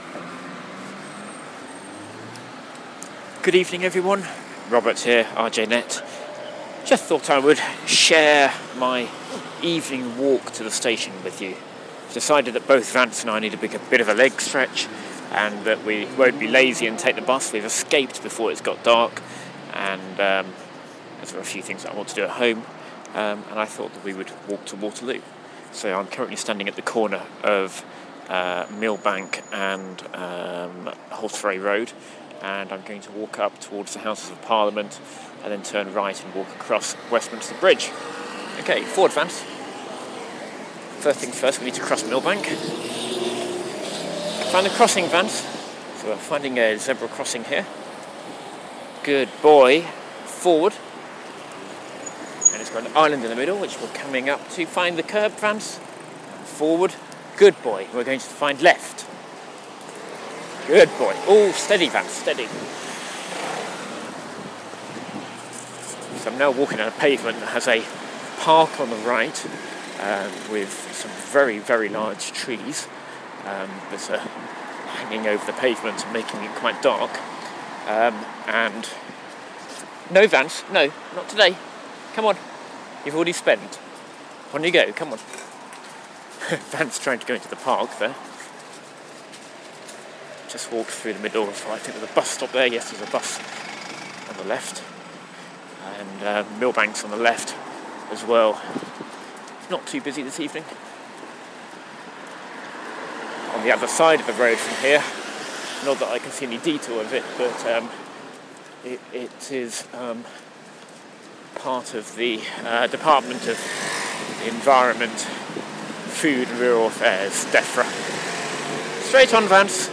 I managed to escape the office whilst the sun was still shining on this lovely October evening, and decided to walk to Waterloo station for our train home. Accompanied by my brilliant guide dog Vance I took a route past the Palace of Westminster, across Westminster Bridge and along South Bank - with plenty of ambient sounds to keep Audioboo listeners happy.